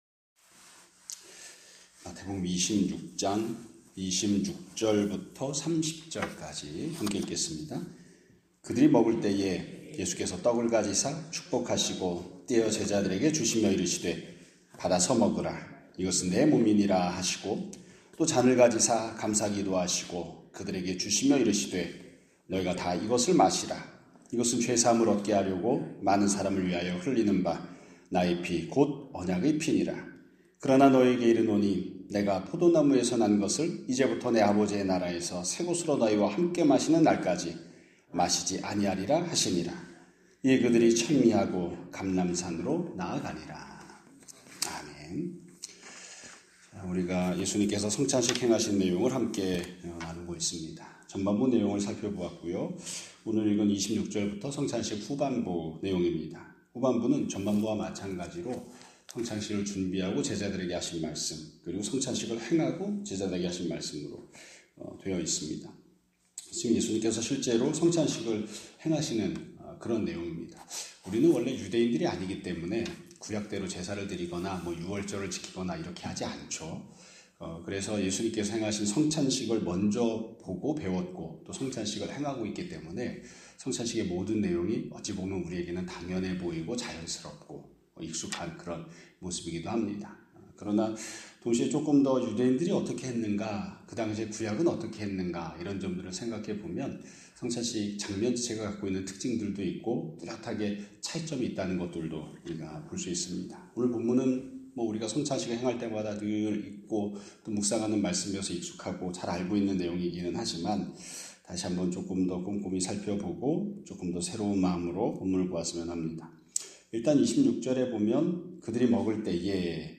2026년 4월 1일 (수요일) <아침예배> 설교입니다.